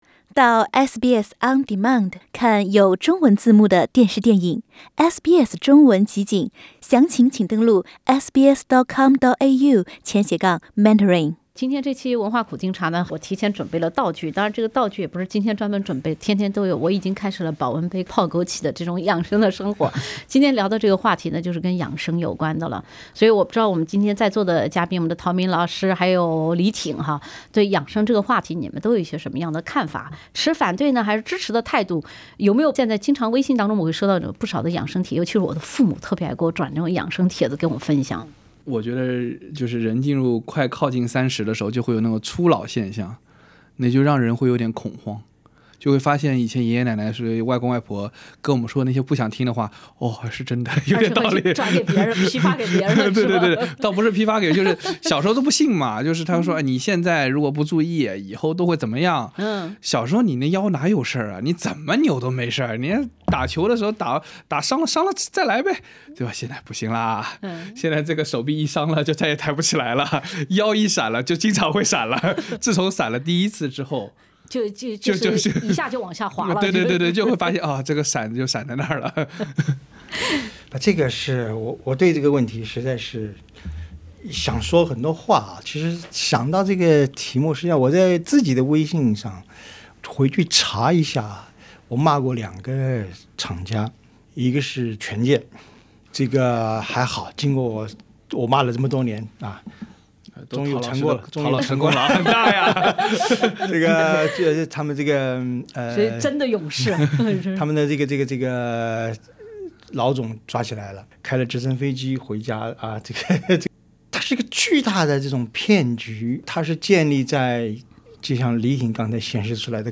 欢迎收听SBS 文化时评栏目《文化苦丁茶》，本期话题是：养生，一朝闪了腰，终身会闪腰？